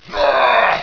pain2.wav